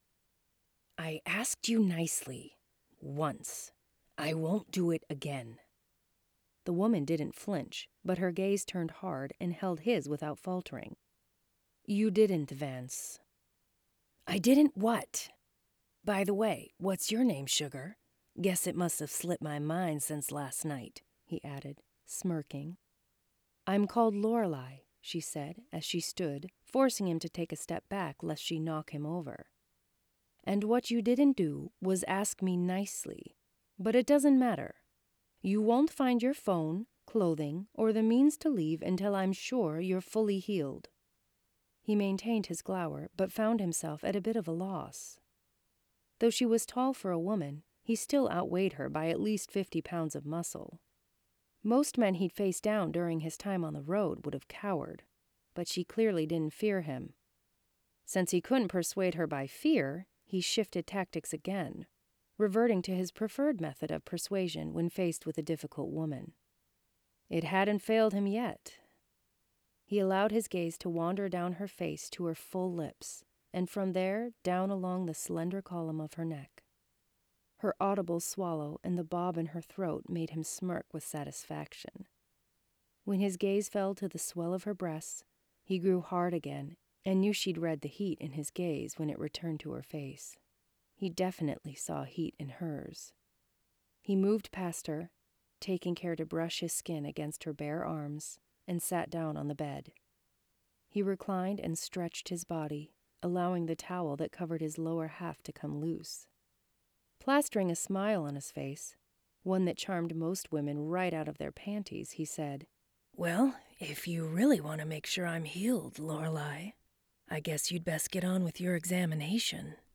Lorelei’s Lyric Audiobook!
loreleis-lyric-audiobook-sample-edit.mp3